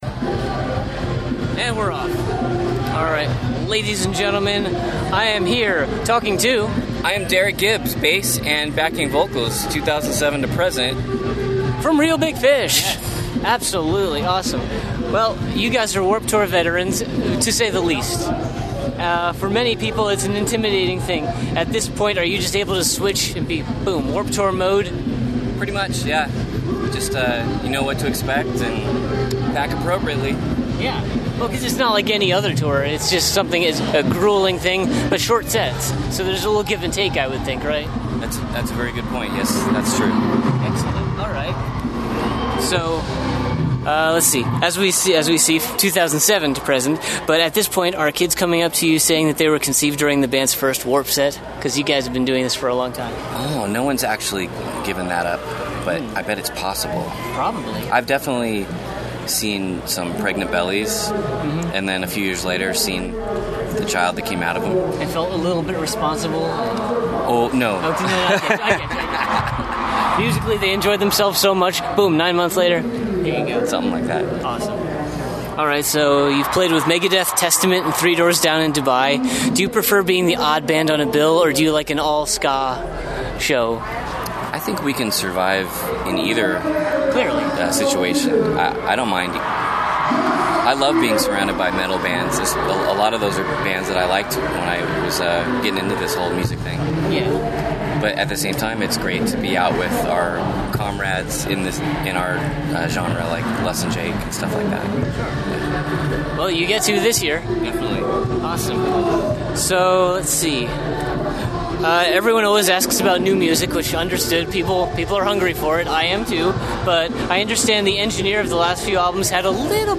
Exclusive Interview: Reel Big Fish (2016)
72-interview-reel-big-fish.mp3